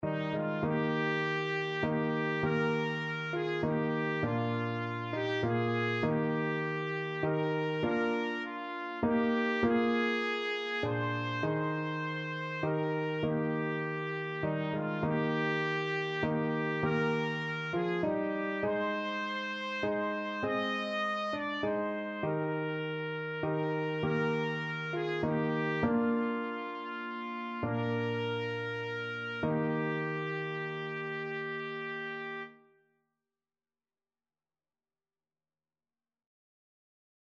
6/4 (View more 6/4 Music)